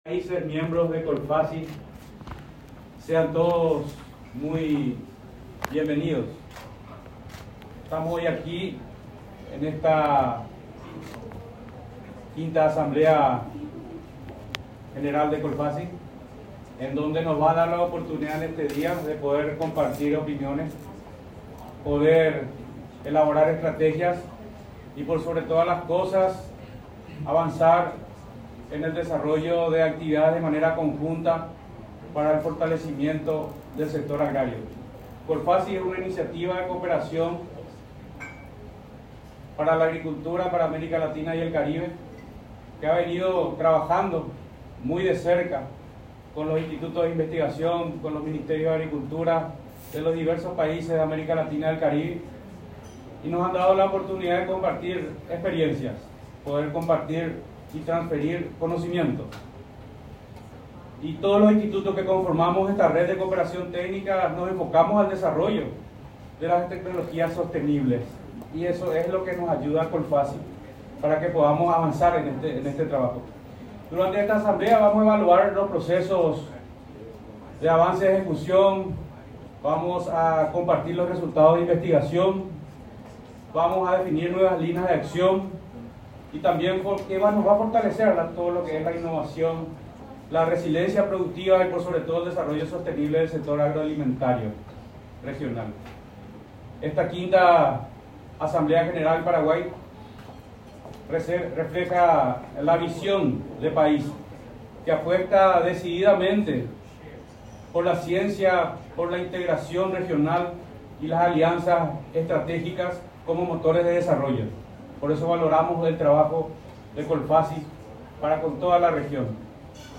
Durante la apertura del evento que se desarrolló el 9 y 10 de marzo en Paraguay, el presidente del Instituto Paraguayo de Tecnología Agraria (IPTA), Eduardo Esteche, destacó que esta iniciativa promueve la cooperación científica y tecnológica en agricultura, permitiendo compartir experiencias, transferir conocimientos y avanzar en el desarrollo de tecnologías sostenibles para el sector productivo.